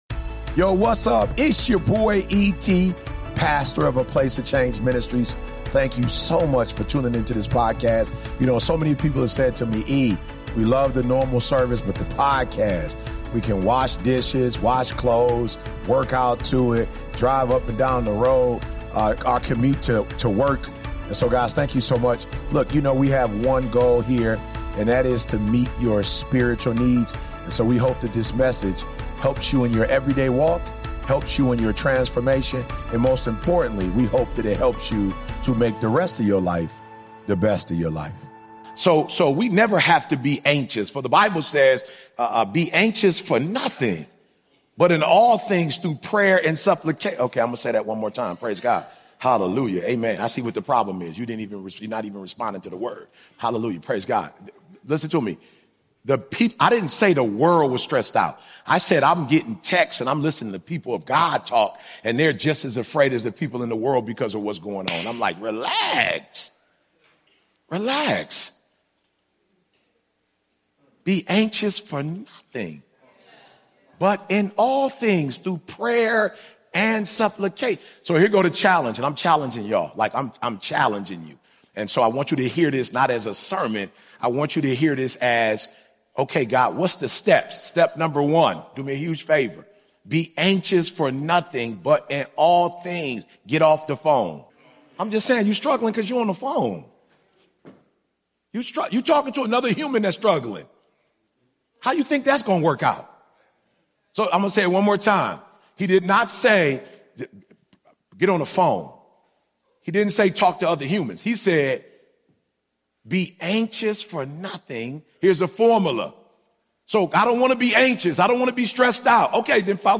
In this unapologetic sermon, Dr. Eric Thomas exposes the lie too many believers are living: calling it a "career" when it's really disobedience in disguise.